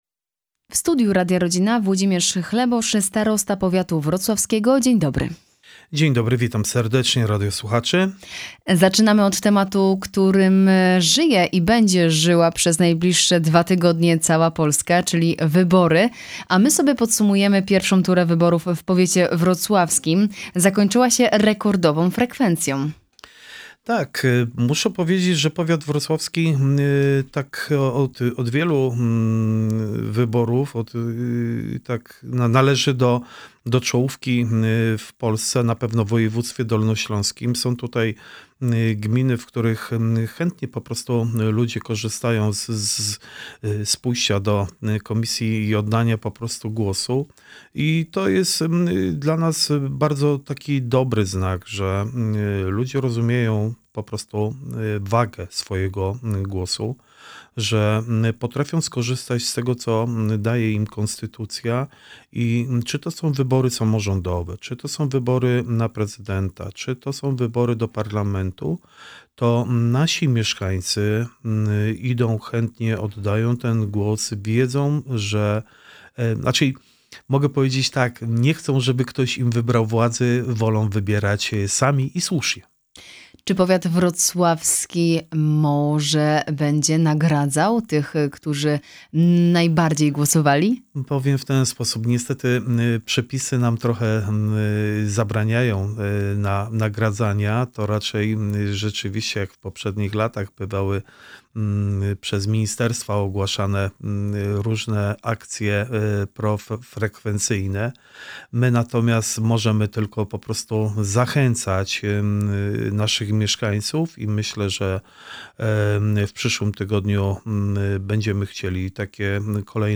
Gościem Radia Rodzina był Włodzimierz Chlebosz, Starosta Powiatu Wrocławskiego.
Cała rozmowa: